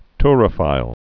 (trə-fīl, tyr-)